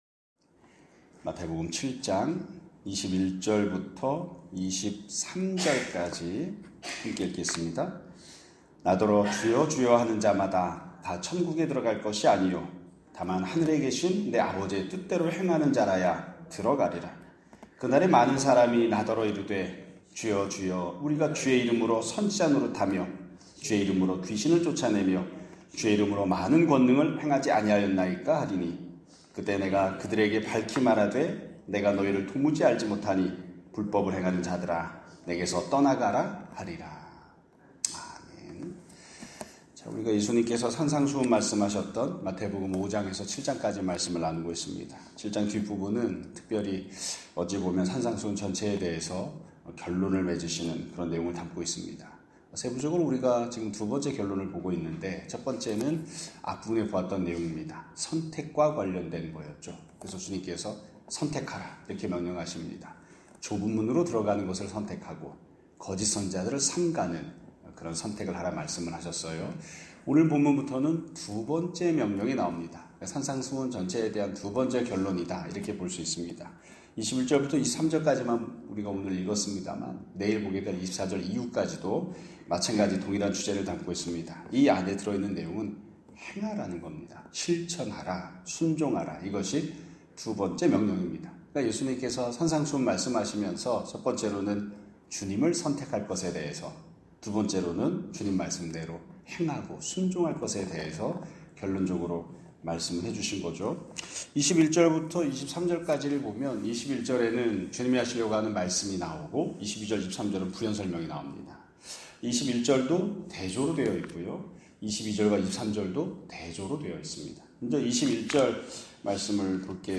2025년 7월 2일(수요 일) <아침예배> 설교입니다.